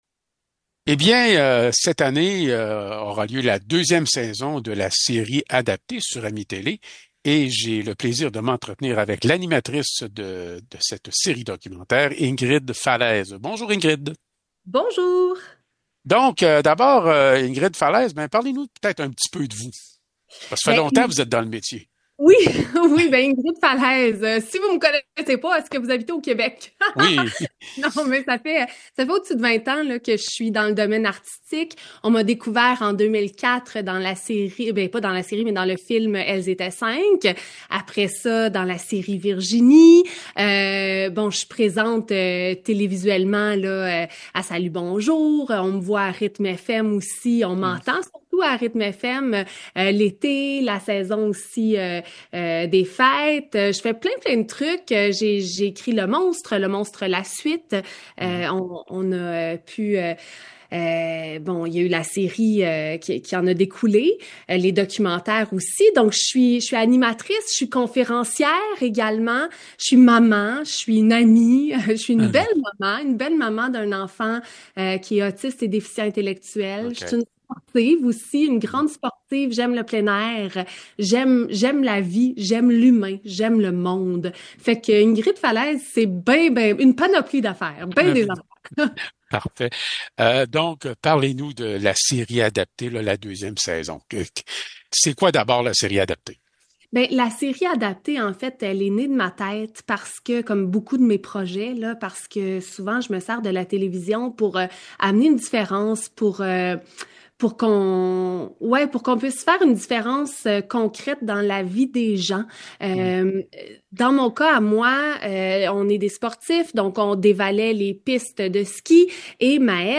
Nous continuons nos entrevues avec les artisans d’AMI-Télé. Ce mois-ci, je m’entretien avec Ingrid Falaise, qui présente la deuxième saison d’adapté, une série documentaires où l’on nous raconte des histoires de familles dont un membre est en situation de handicap et qui pour la première fois réalisent leur activité physique de rêve, adaptée à leur condition.